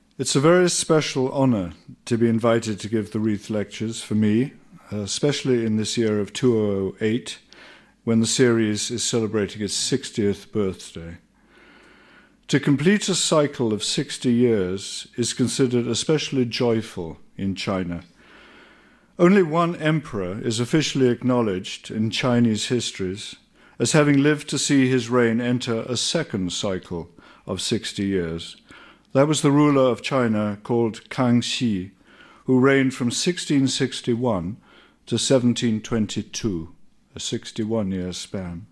史景遷的談話錄音 / 2008年6月3日英國廣播公司錄音 BBC廣播4頻道Reith Lectures